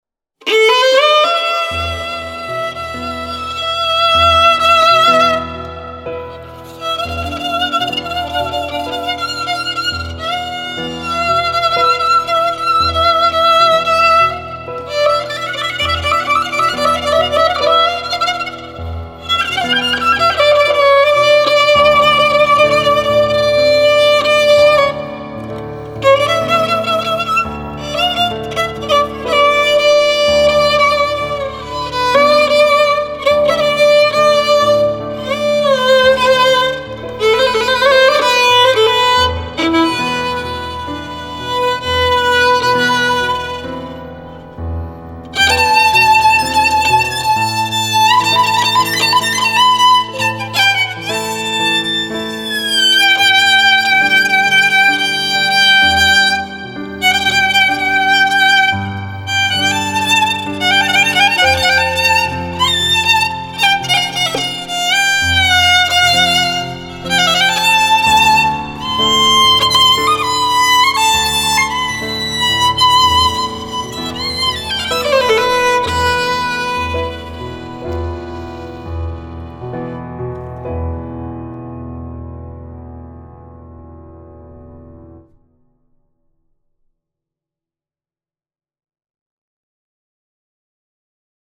دوئت